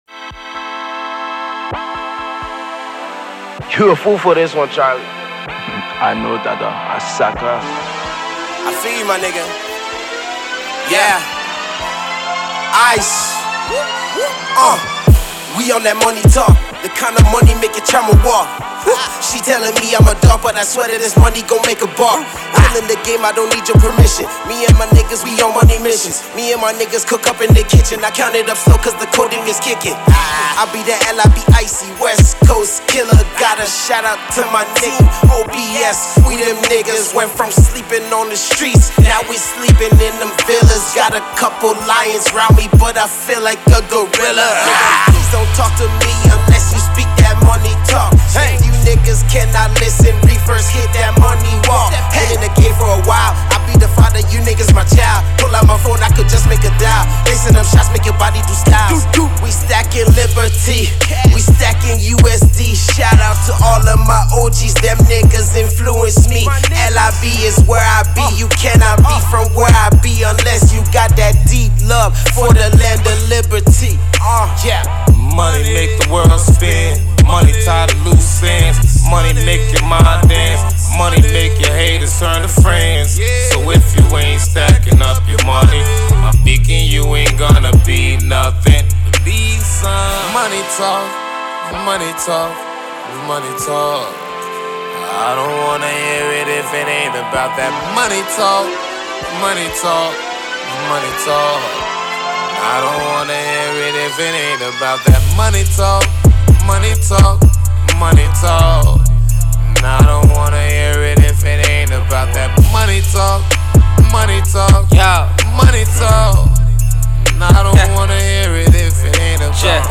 Hip-Hop
Hip-Hop/rap tune